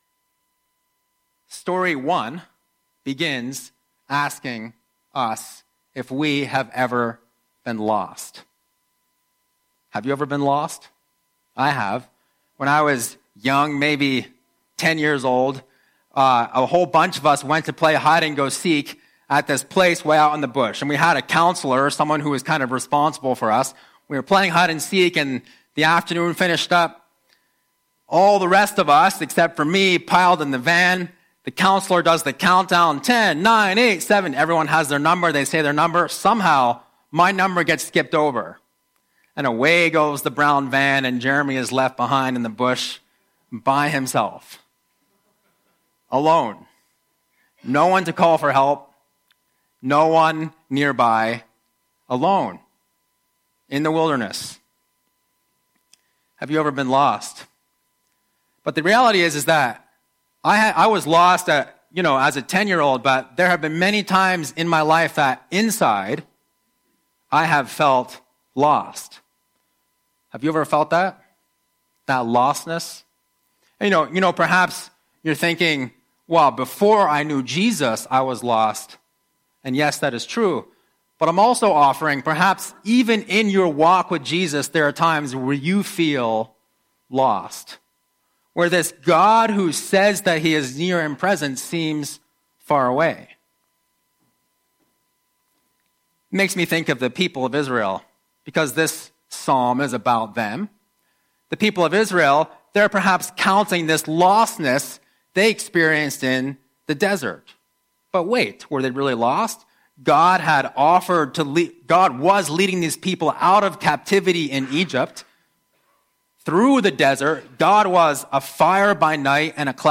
Sunday Messages | Transformation Church
Note: This recording does not include the reading of Psalm 107.